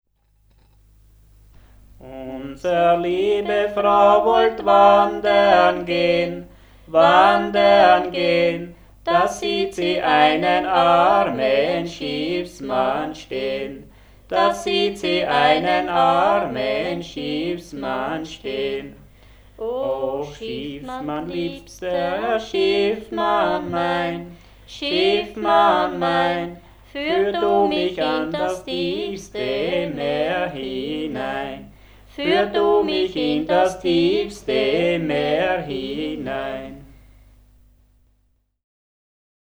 Traditional music of the Wechsel, styrian-lower austrian border region, 100 kms south of Vienna. Volume 1 „The religious song“ sung during the farmer’s traditional two-night corpse-watch at the bier in the house of the deceased. 192 songs with text, music and incipits, 3 CDs with historical recordings, dictionary of local dialect.
Traditional music in the Styrian / Lower Austrian Wechsel-region; songs during the corpse-watch in the farmhouse of the deceased, CDs, historical recordings, dictionary of local dialect; incipits
Church music
Folk & traditional music